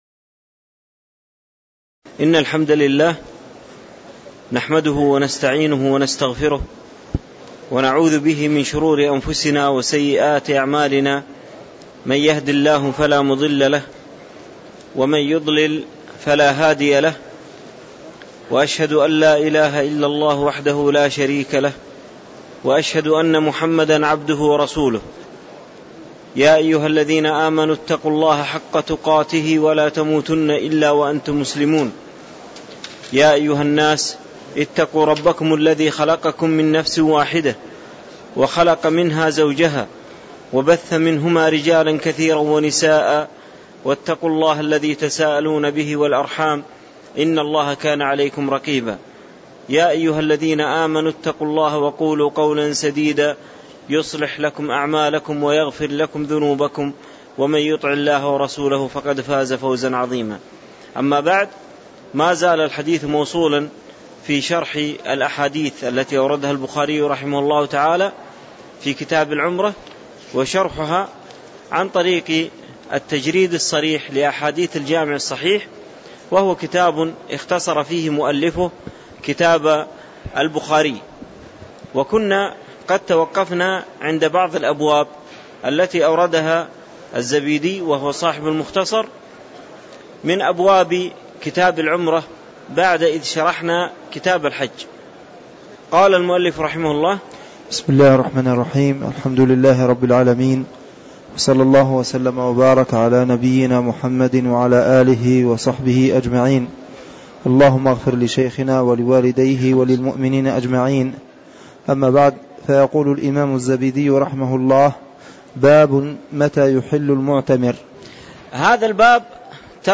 تاريخ النشر ٢٠ ذو القعدة ١٤٣٧ هـ المكان: المسجد النبوي الشيخ